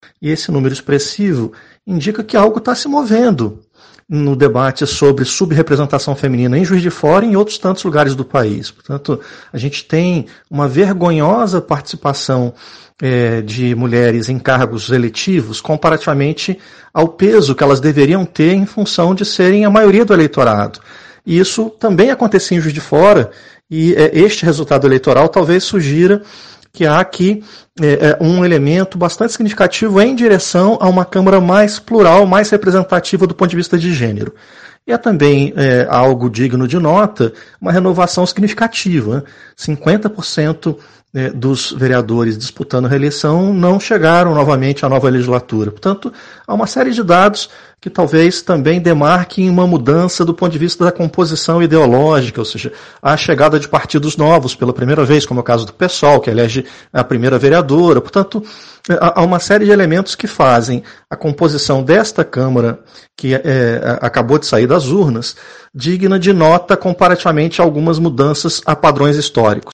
cientista político